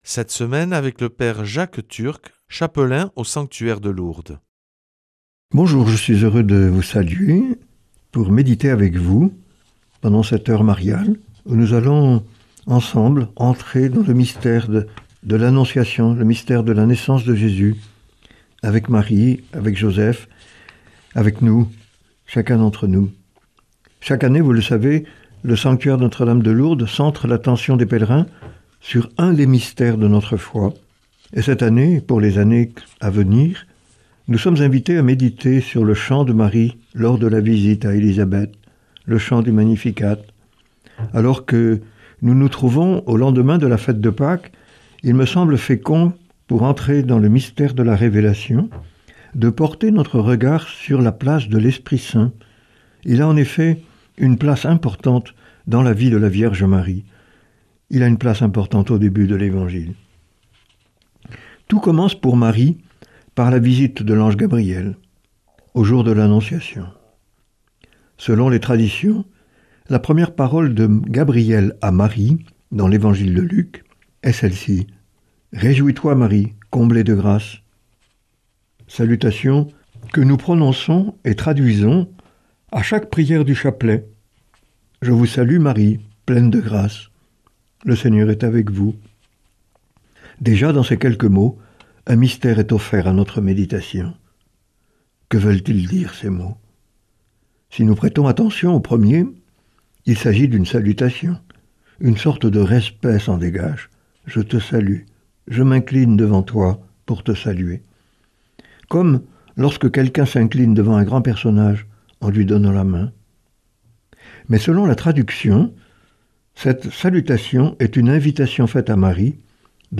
lundi 20 avril 2026 Enseignement Marial Durée 10 min